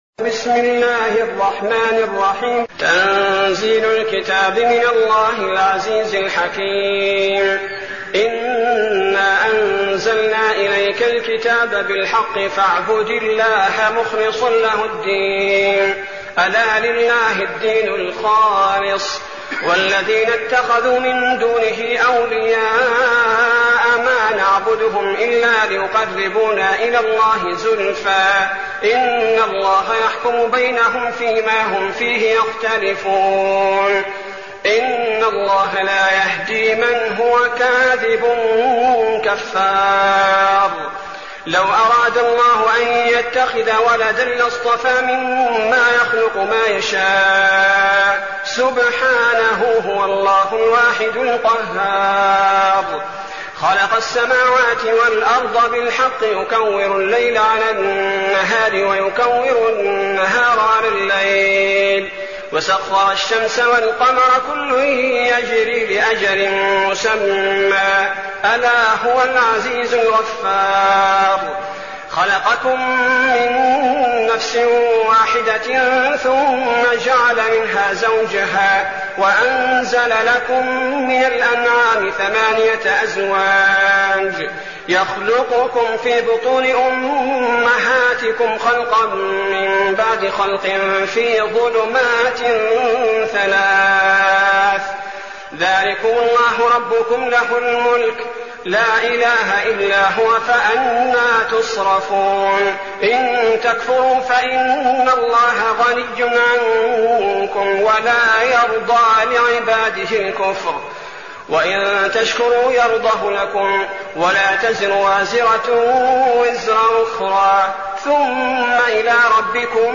المكان: المسجد النبوي الشيخ: فضيلة الشيخ عبدالباري الثبيتي فضيلة الشيخ عبدالباري الثبيتي الزمر